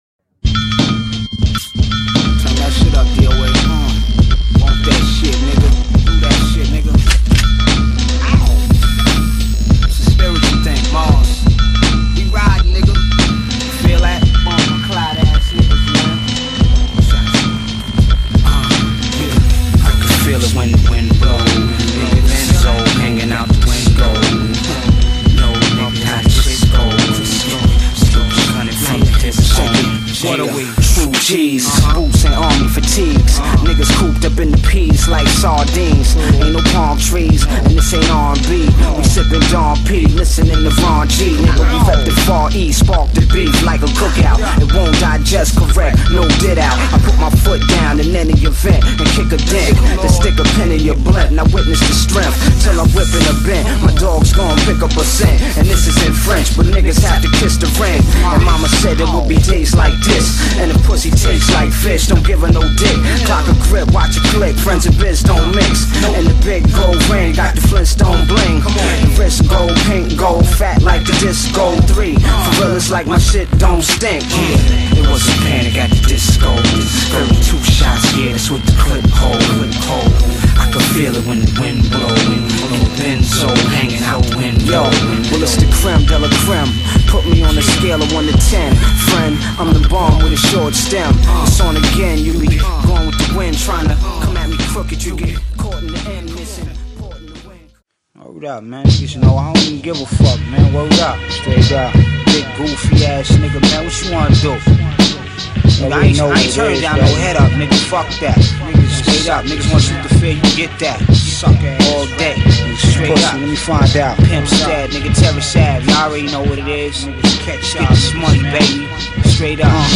プロデュースも自身によるもので、男気に溢れまくった極太ドープビーツでラップ。これぞストレート・ア・Hip Hop！！